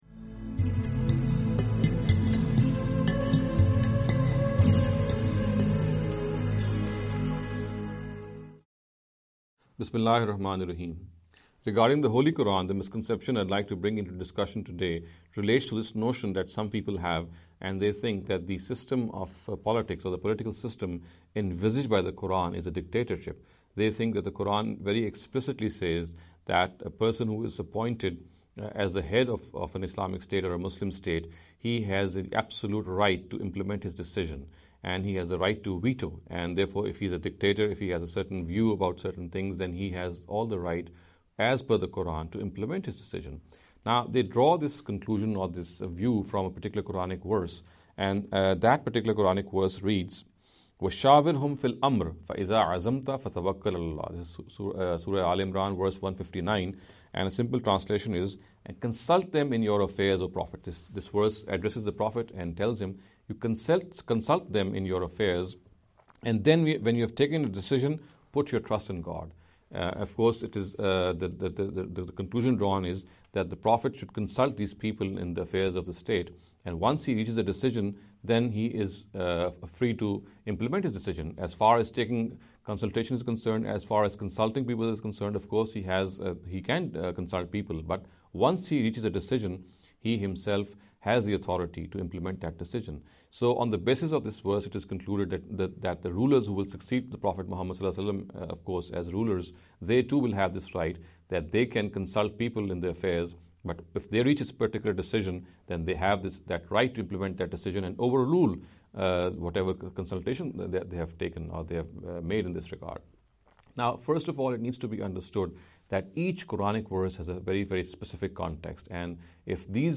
This lecture series will deal with some misconception regarding the Holy Qur’an.